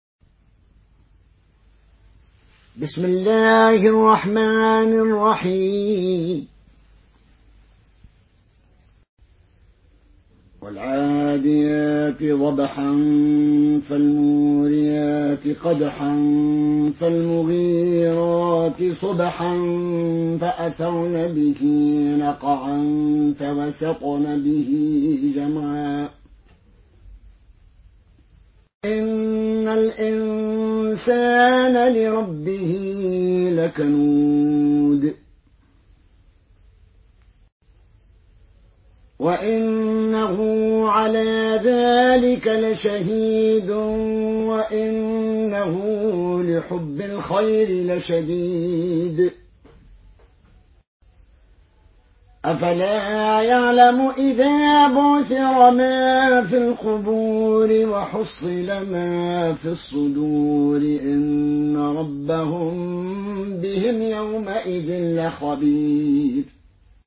Une récitation touchante et belle des versets coraniques par la narration Qaloon An Nafi.